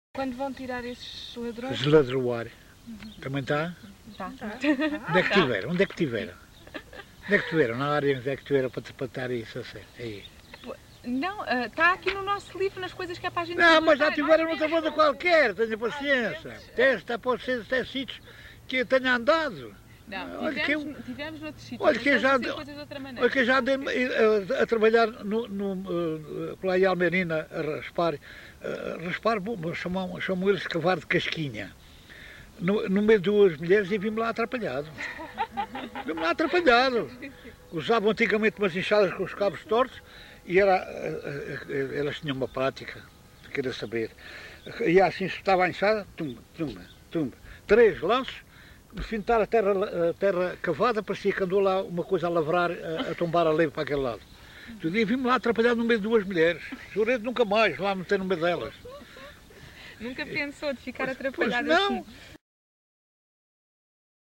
LocalidadeMontalvo (Constância, Santarém)